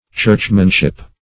Search Result for " churchmanship" : The Collaborative International Dictionary of English v.0.48: Churchmanship \Church"man*ship\, n. The state or quality of being a churchman; attachment to the church.